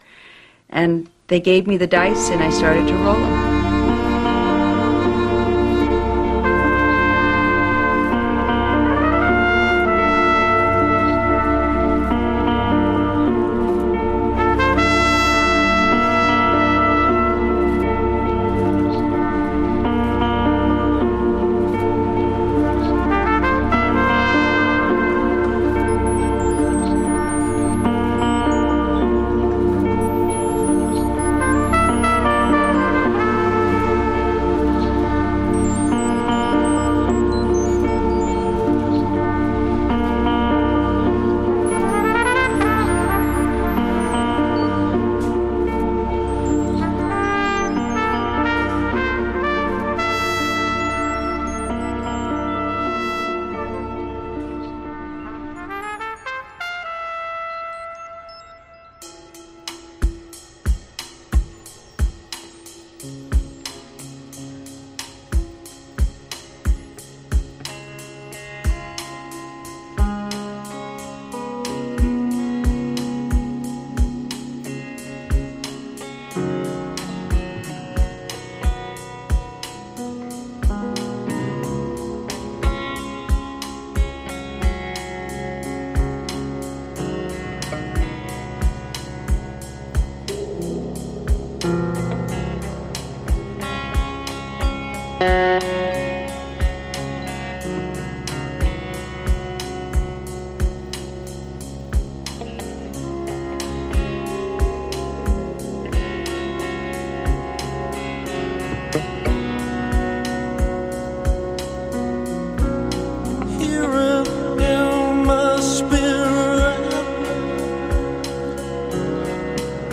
selection of tracks handpicked and mixed